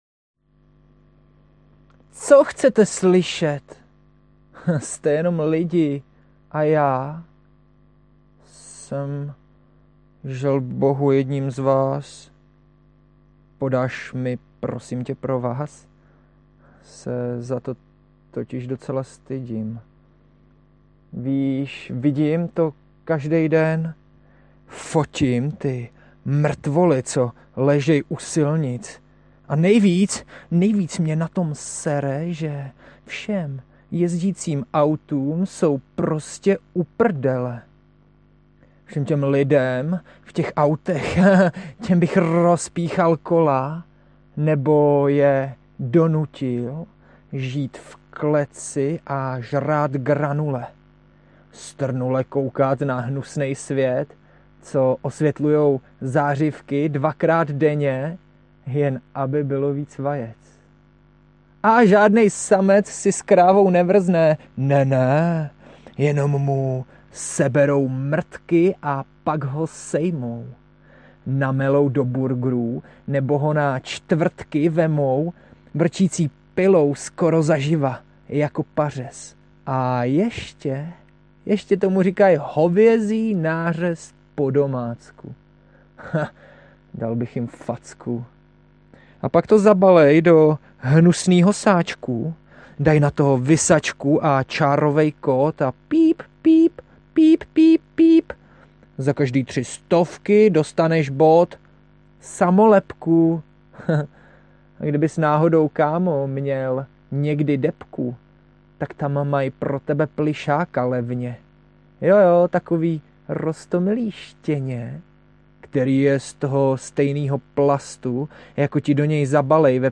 Poezie v próze
Nahrávka je dost amatérská…